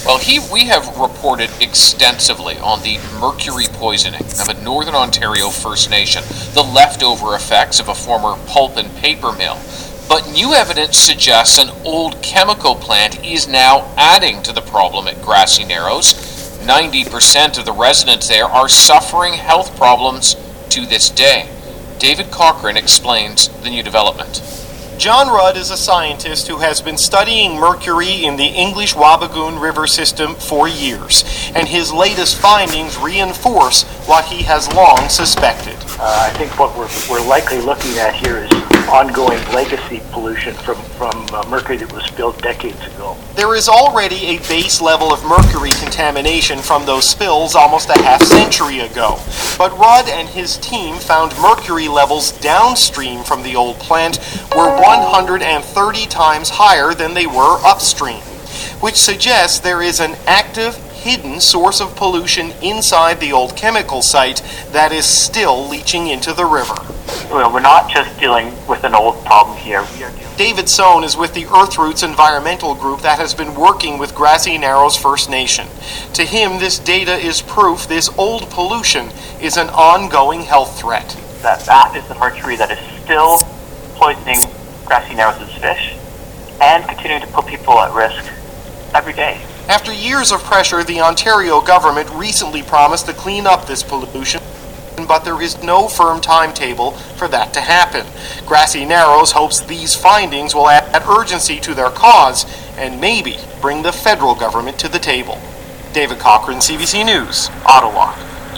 CBC Hourly News – Morning
CBC-World-Report-Feb-28.mp3